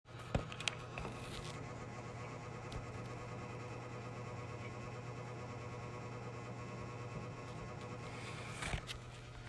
PC vibriert stark
Hallo, mein Pc vibriert extrem stark und laut wenn der Lüfter gegen meine Radiator geht.
Ja wenn der Lüfter wieder zu näh an dem Radiator ist, dann hört es sich an, als ob etwas im Lüfter wäre wie zum Beispiel ein Kabel (ist aber keins habe ich schon nachgesehen).